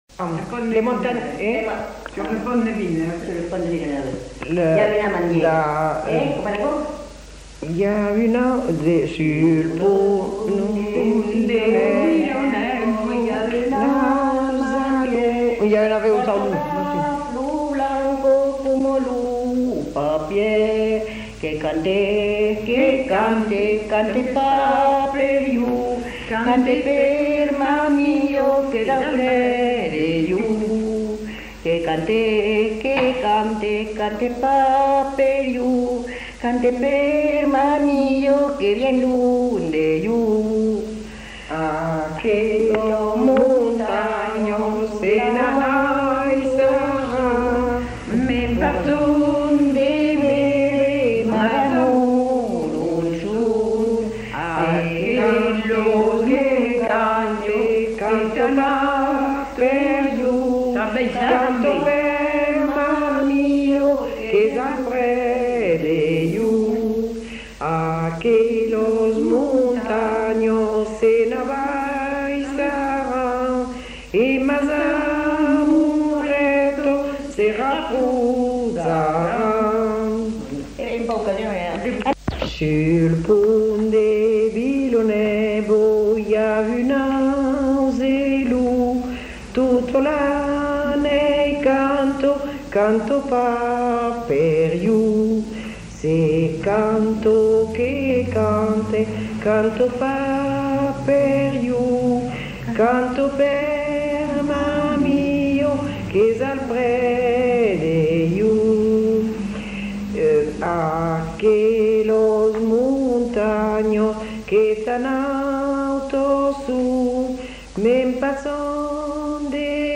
Aire culturelle : Haut-Agenais
Lieu : Cancon
Genre : chant
Effectif : 2
Type de voix : voix de femme
Production du son : chanté
Classification : lyriques